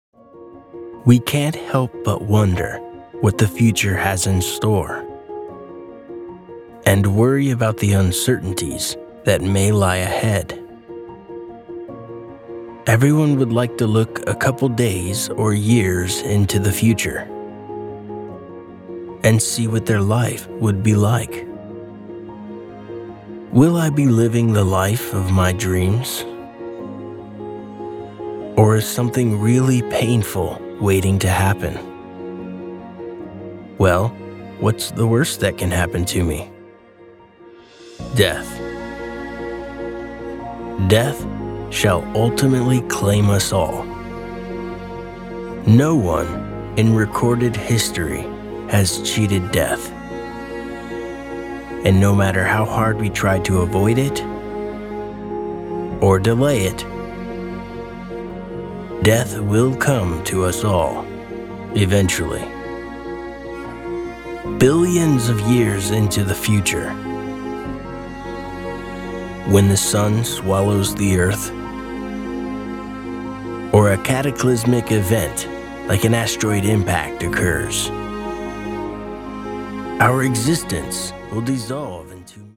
Documentários
I am known for the conversational personality, animated acting and raspy sound I bring to the performance.
I have a professional recording studio at home as well as strong mixing & mastering skills.
Sennheiser MKH416 shotgun microphone